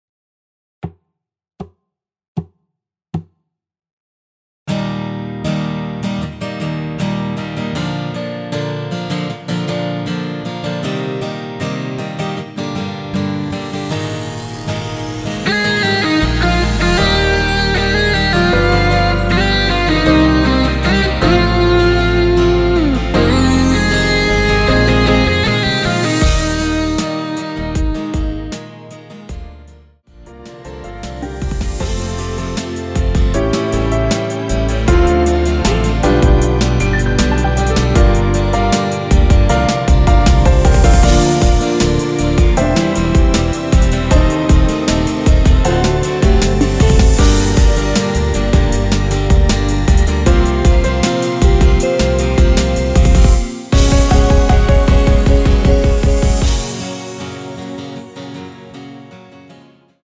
전주 없이 시작하는 곡이라서 노래하기 편하게 카운트 4박 넣었습니다.(미리듣기 확인)
원키에서(-1)내린 MR입니다.
Eb
앞부분30초, 뒷부분30초씩 편집해서 올려 드리고 있습니다.